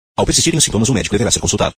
Famoso alerta em propagandas de remédio.